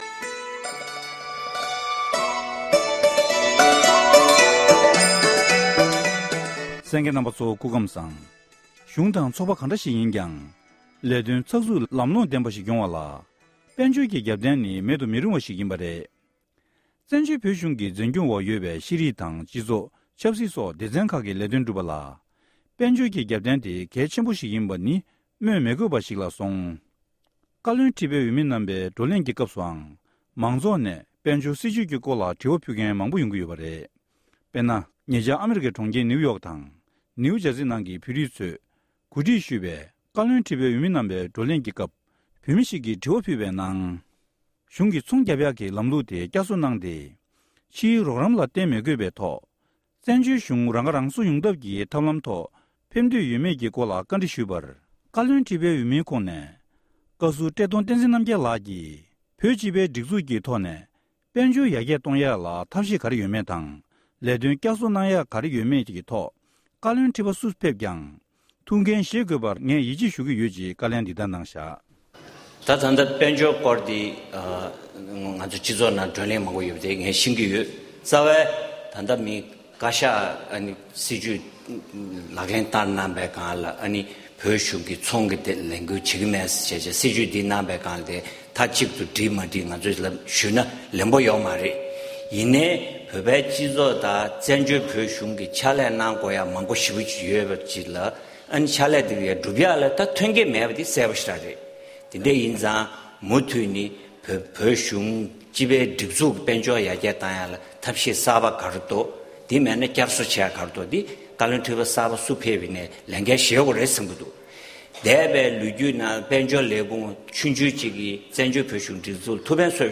བཀའ་བློན་ཁྲི་པའི་འོས་མི་རྣམ་པས་བགྲོ་གླེང་སྐབས་མ་འོངས་བཙན་བྱོལ་བོད་གཞུང་གི་དཔལ་འབྱོར་སྲིད་ཇུས་སྐོར་དགོངས་ཚུལ་གསུངས་པ།